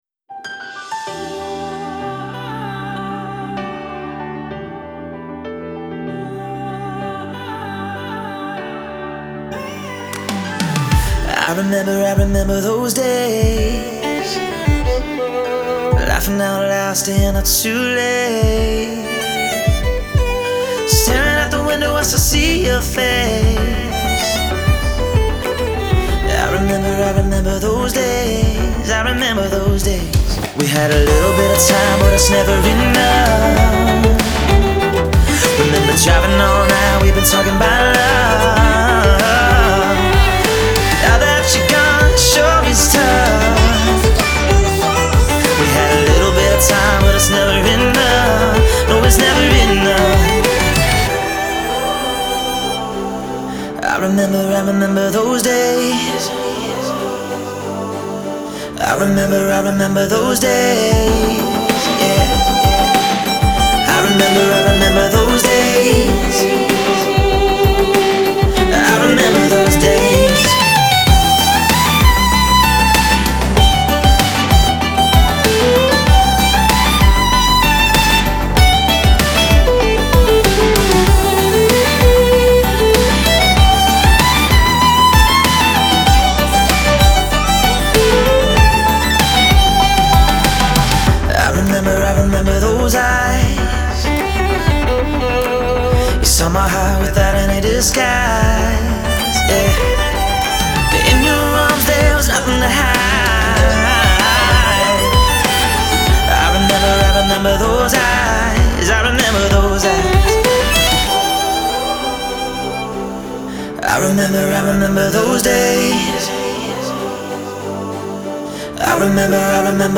Genre : Classical, Electronic